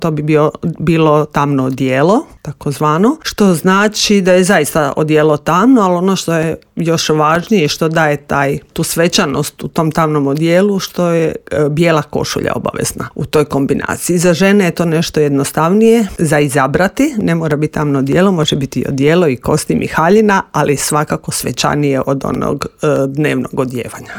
Intervjuu